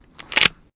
weapon_foley_pickup_09.wav